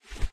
Grab.ogg